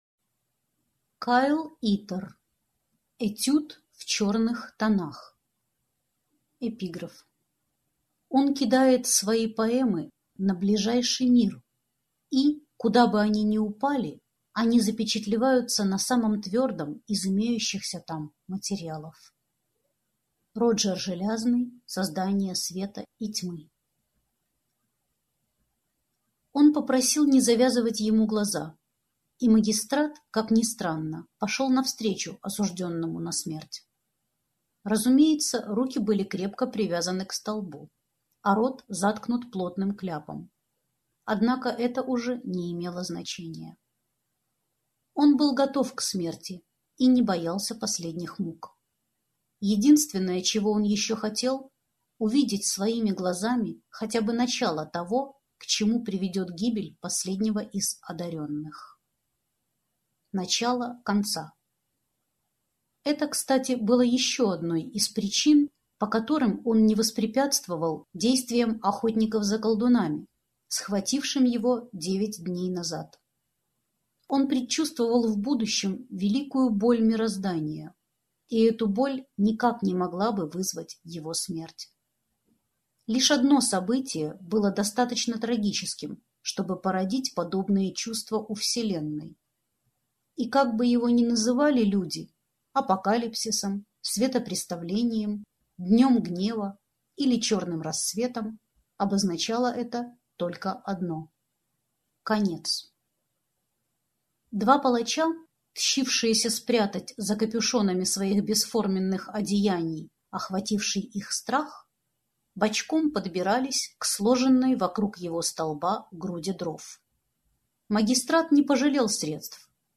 Аудиокнига Этюд в черных тонах | Библиотека аудиокниг
Прослушать и бесплатно скачать фрагмент аудиокниги